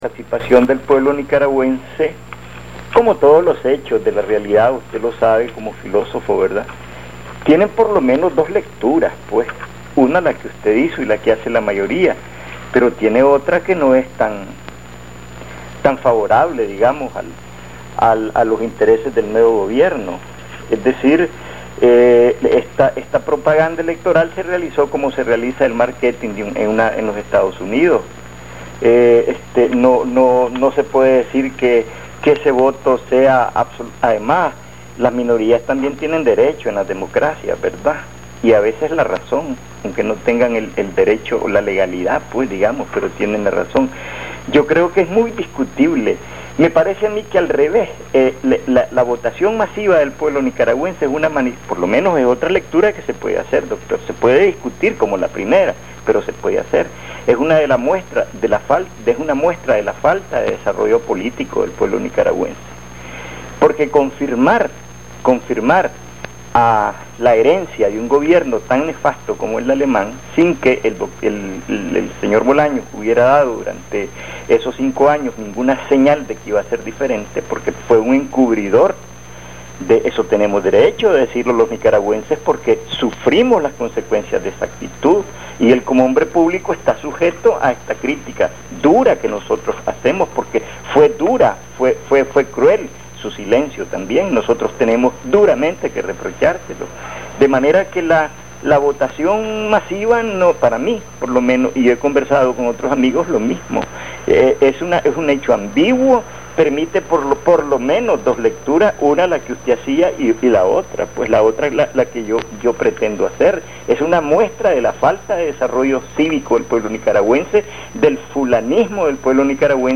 Extractos de participaciones en la emisora La Primerísima y en la 580 en el programa "Noticias y Comentarios".